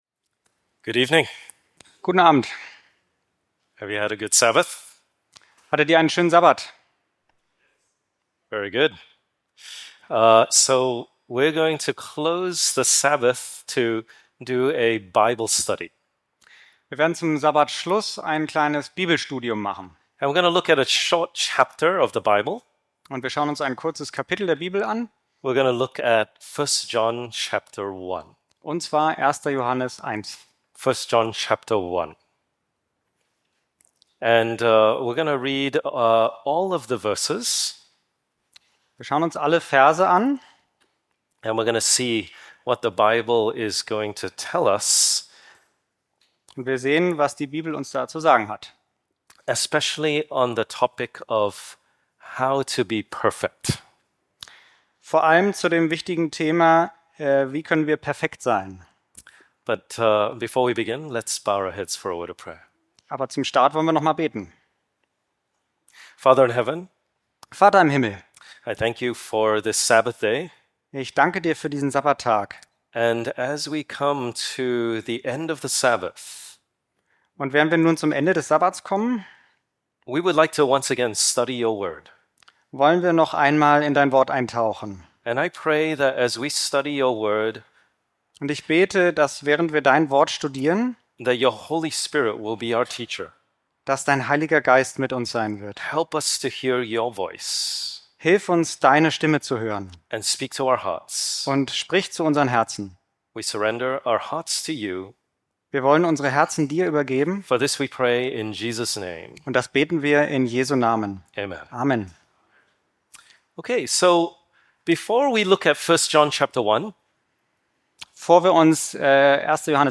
DasWort - Predigten Podcast